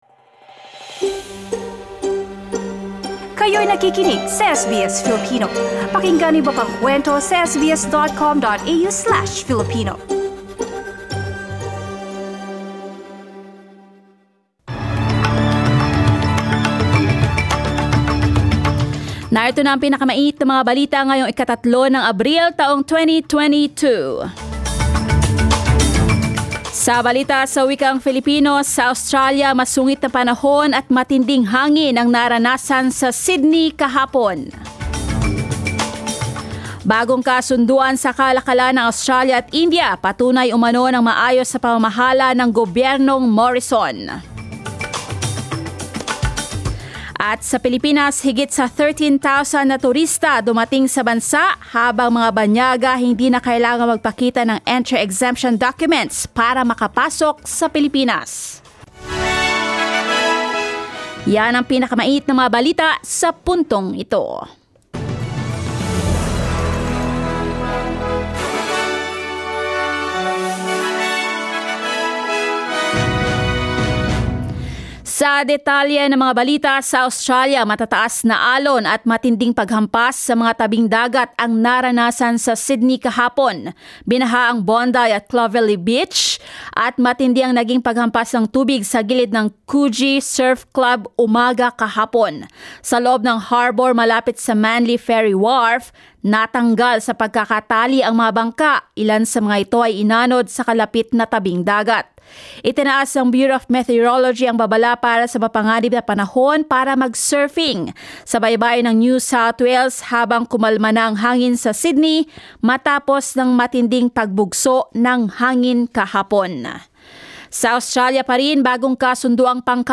SBS News in Filipino, Sunday 3 April